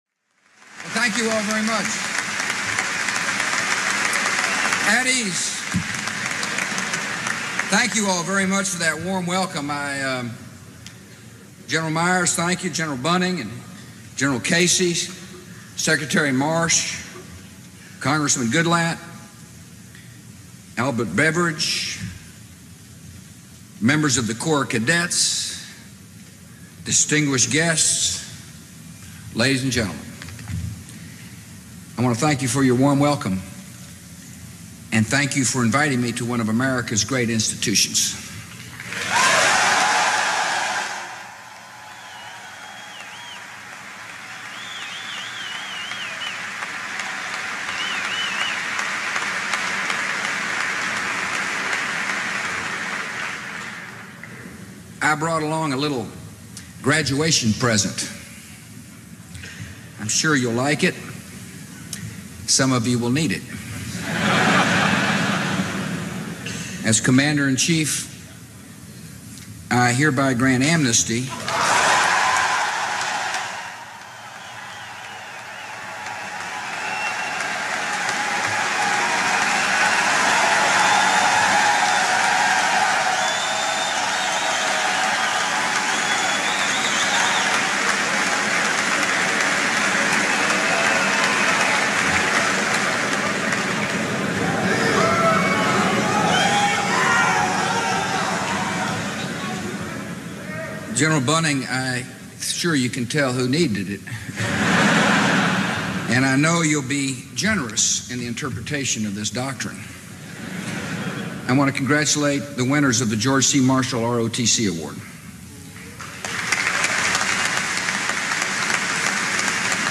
George W. Bush: Address on National Security at the Virginia Military Institute (transcript-audio-video)
Addressing cadets and staff, President George W. Bush explained America's plans in the war against terrorism during the ceremony for the George C. Marshall ROTC Award Seminar on National Security at Cameron Hall at the Virginia Military Institute in Lexington, Va., Wednesday, April 17.